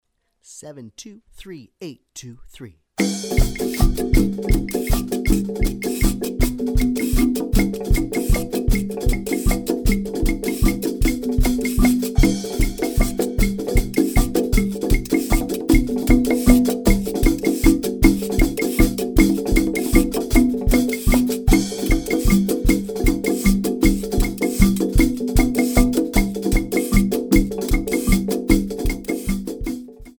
Fast Triple Meter - 155 bpm